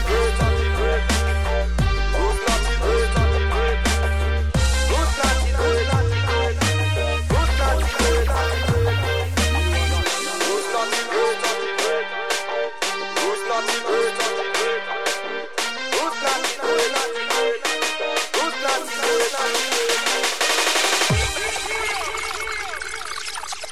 TOP >Vinyl >Drum & Bass / Jungle
TOP > Jump Up / Drum Step